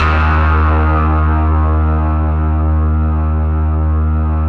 RESMET D#2-R.wav